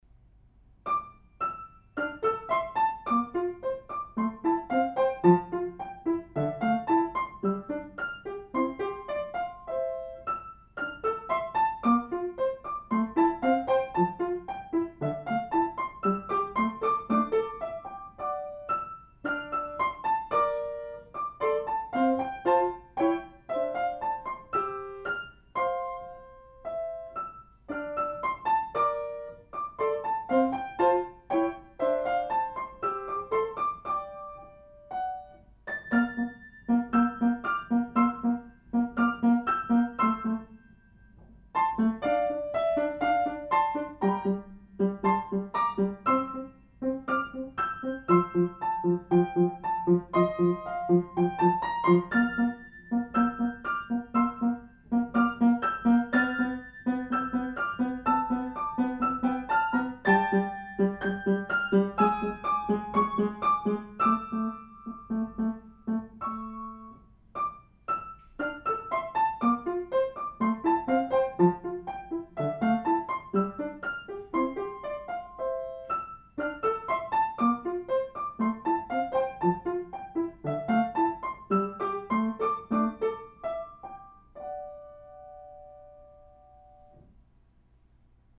Played on piano except where noted